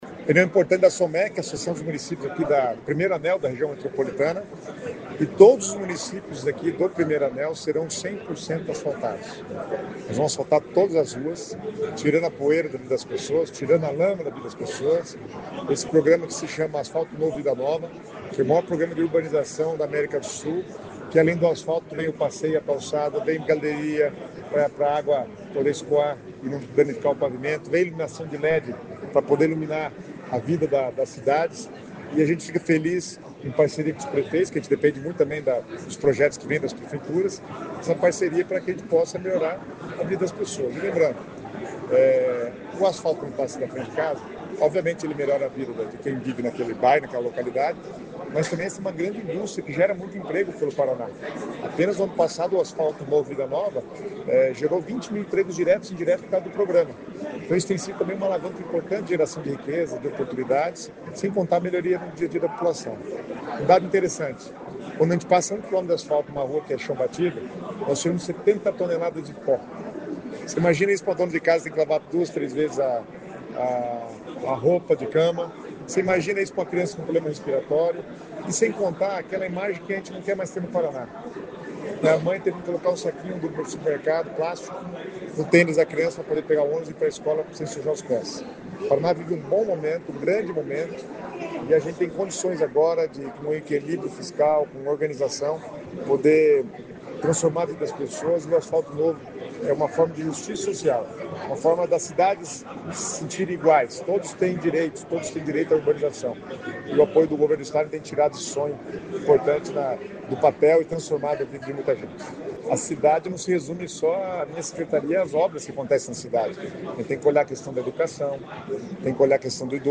Sonora do secretário Estadual das Cidades, Guto Silva, sobre as liberações desta terça pelo Asfalto Novo, Vida Nova